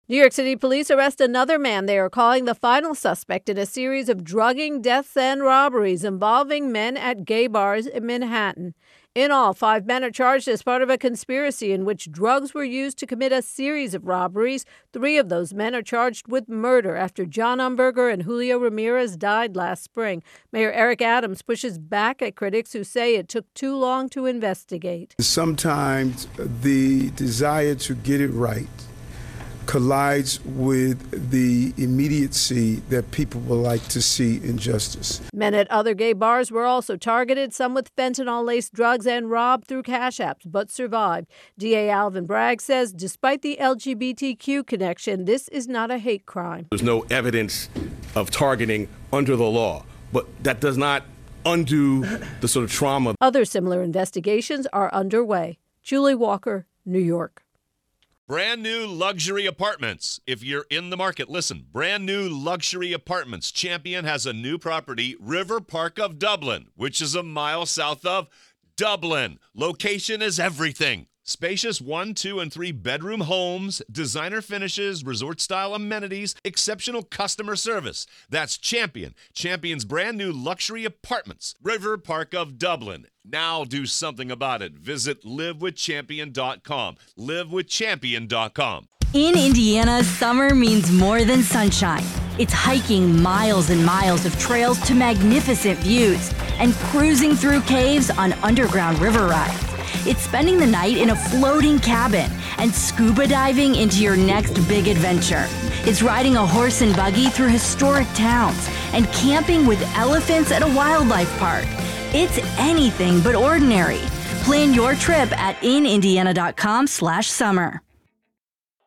reports on NYC Drugging Robberies Arrest.